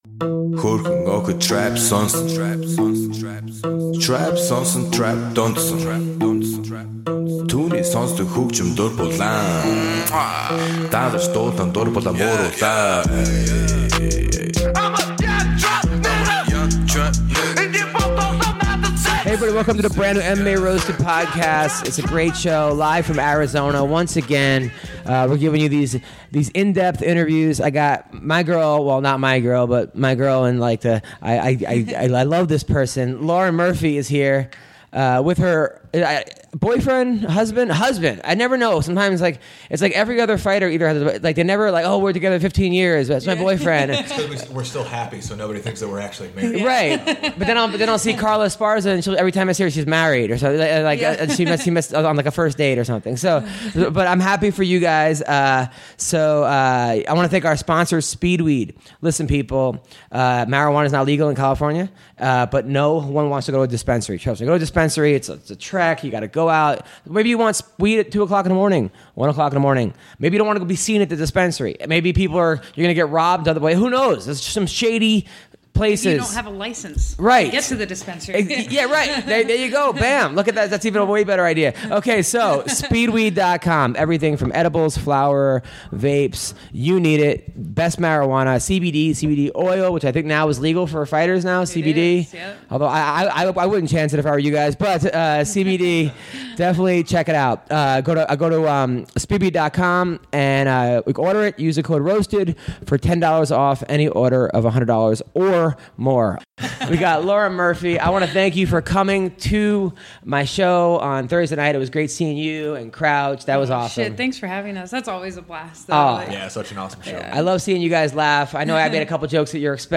is joined in studio